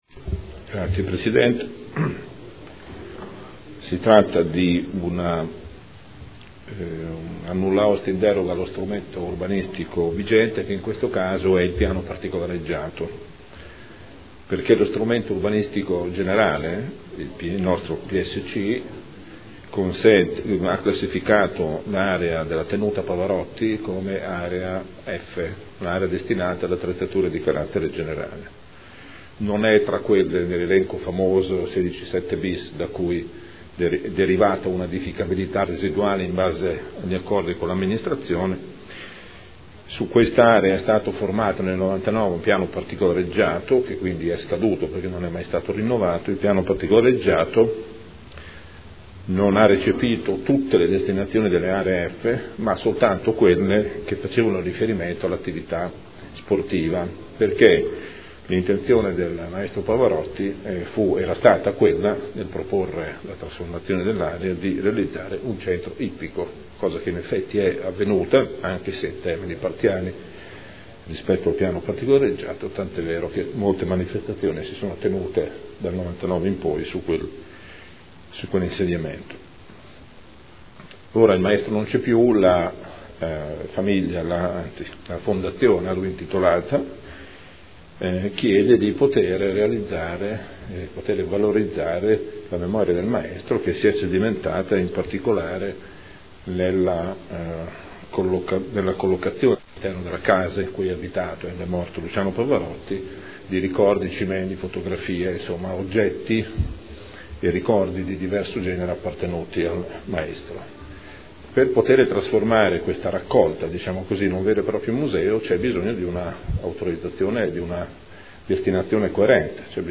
Gabriele Giacobazzi — Sito Audio Consiglio Comunale
Seduta del 31 marzo. Proposta di deliberazione: Proposta di progetto - Casa Museo del Maestro Luciano Pavarotti – Stradello Nava – Z.E. 2400 – Nulla osta in deroga agli strumenti urbanistici comunali – Art. 20 L.R. 15/2013